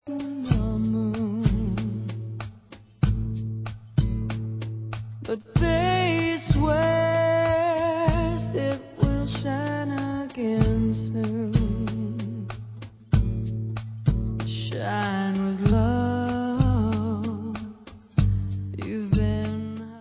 sledovat novinky v kategorii Country